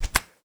Foley Sports / Golf / Golf Hit Iron.wav
Golf Hit Iron.wav